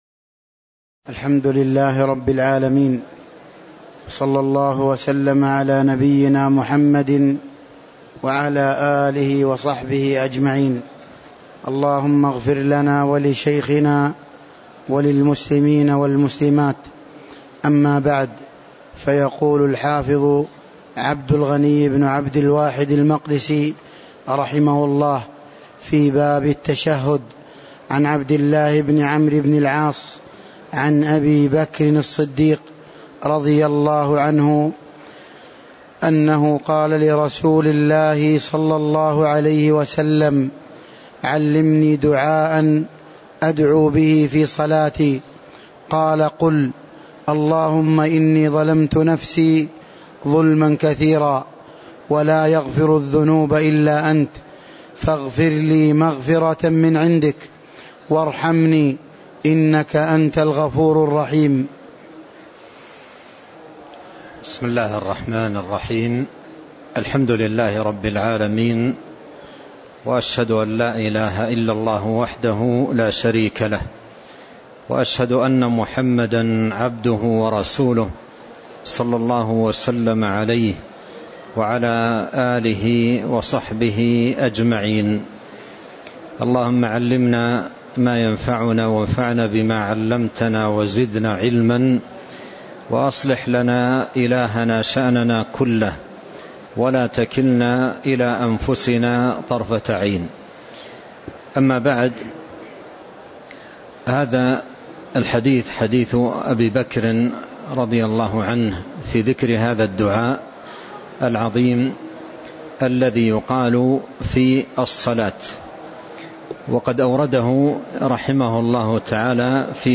تاريخ النشر ١٥ ربيع الثاني ١٤٤٤ هـ المكان: المسجد النبوي الشيخ: فضيلة الشيخ عبد الرزاق بن عبد المحسن البدر فضيلة الشيخ عبد الرزاق بن عبد المحسن البدر باب التشهد (016)